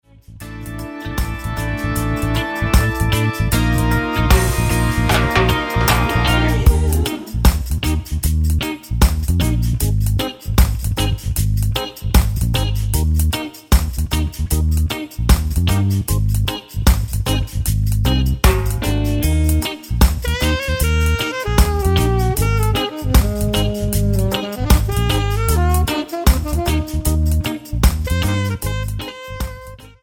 --> MP3 Demo abspielen...
Tonart:D-Eb mit Chor